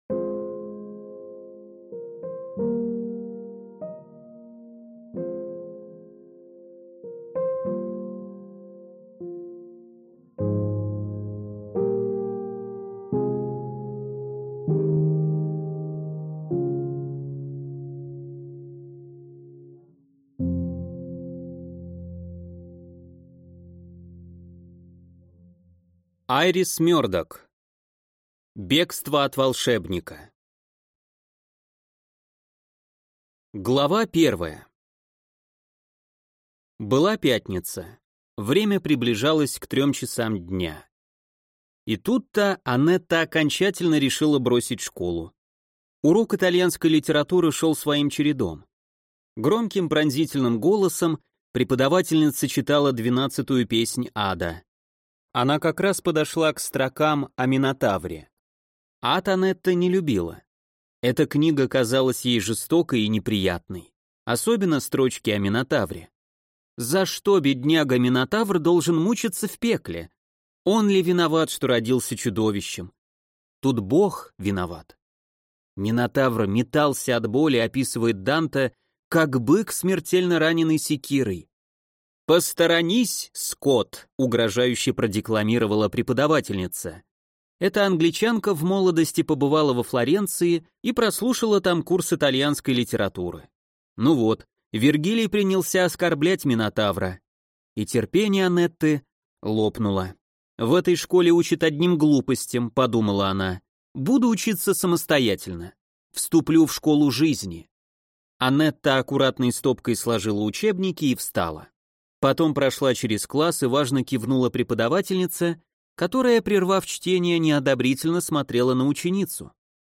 Аудиокнига Бегство от волшебника | Библиотека аудиокниг